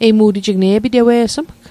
Pronunciation Guide: ee·bi·de·wee·sêm